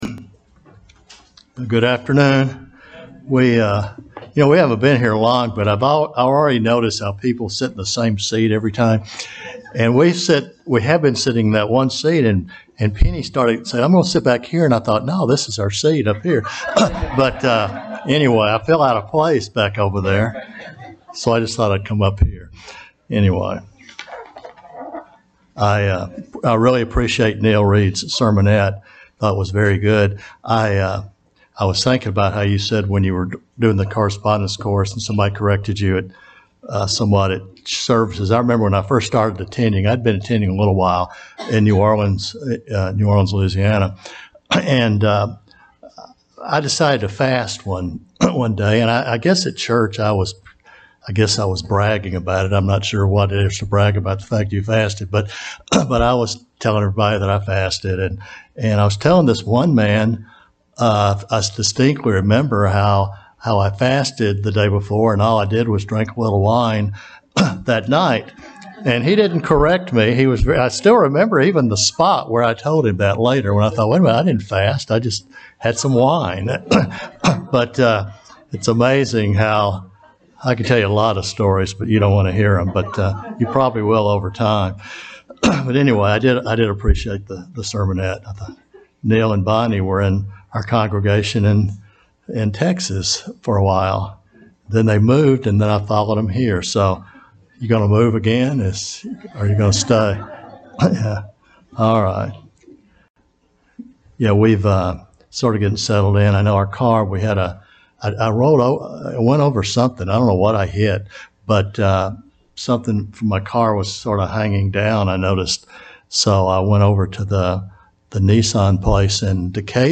When Moses was told to put a bronze snake on a pole when the Israelites were being bitten by poisonous snakes, we can wonder why. This sermon takes a look at why God would instruct Moses to do this.